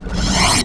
VEC3 Reverse FX
VEC3 FX Reverse 46.wav